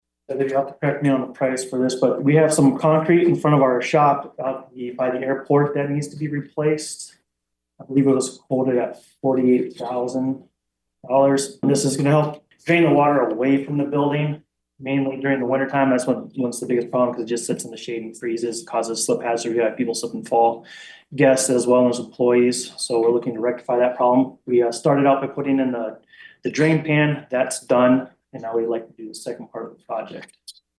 talked to the council about some concrete that needs replacing.